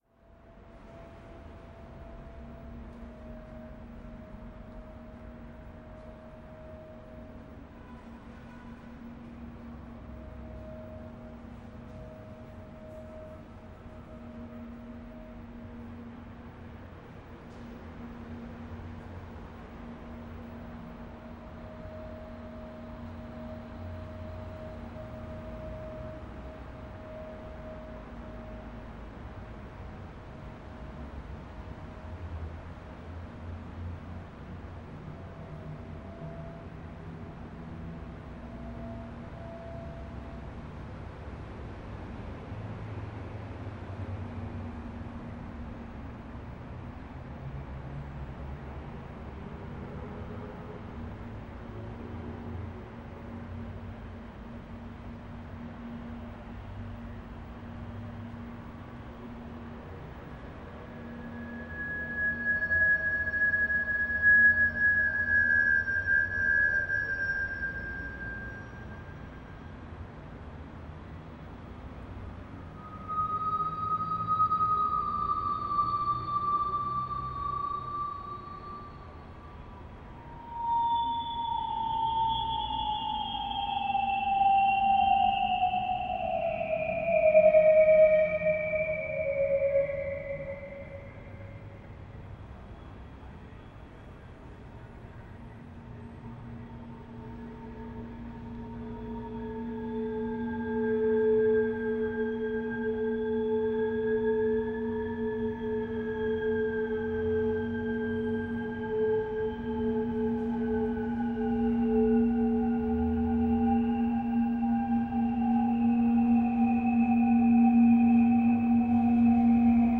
A 50-meter long public underpass is transformed into a sonic chamber using controlled feedback.
Feedback loops were controlled individually with a computer to avoid infinite loudness growth, and to transform their sound, creating some sort of electronic feedback choir in perpetual state of instability.
The characteristics of the site -having both ends open to outdoor urban spaces- made it possible for the system to react to ambient sound in an organic way, incorporating the sound of wind, voices, traffic, planes, birds, etc. to the sonic environment of the tunnel.
Location recording of the installation. Fixed shot at the east entrance of the tunnel